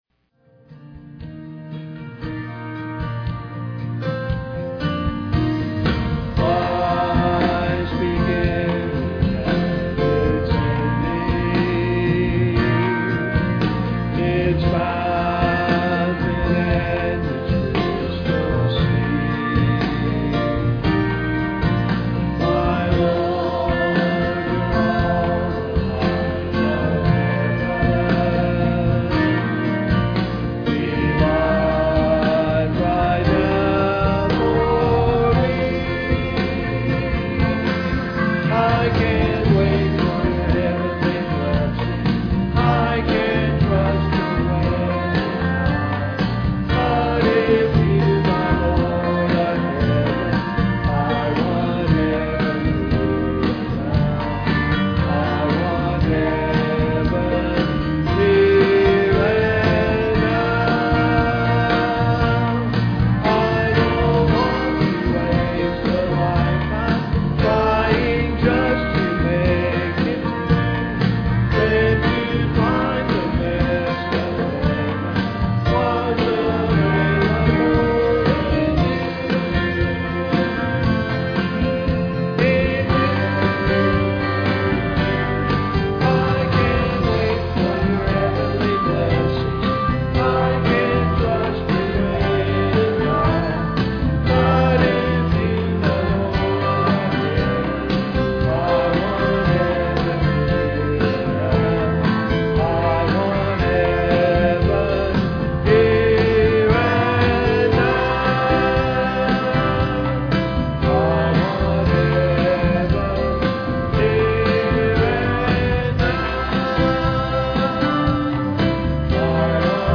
5th Sunday Night Sing
reading and vocal solo
piano solo
guitar and vocal